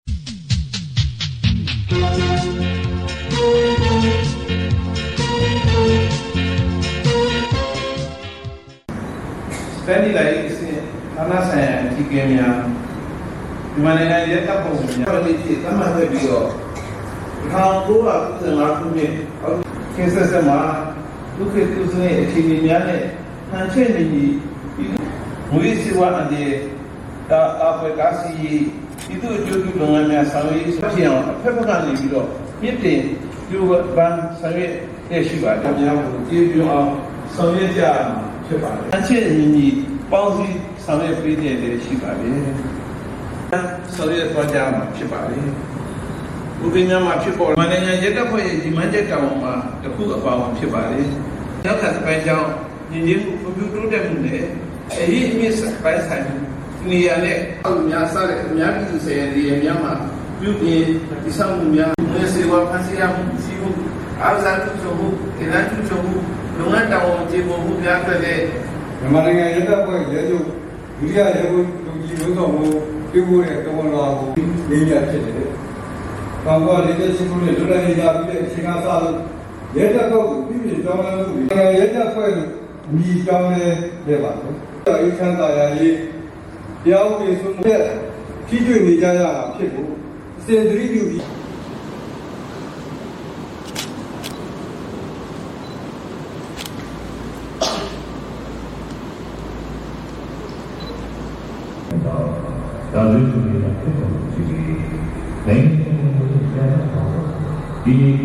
တနင်္သာရီတိုင်းဒေသကြီးဝန်ကြီးချုပ် ဦးမြတ်ကို နှစ်(၆၀)ပြည့် မြန်မာနိုင်ငံရဲတပ်ဖွဲ့နေ့အခမ်းအနား သို့တက်ရောက် ထားဝယ် အောက်တိုဘာ ၁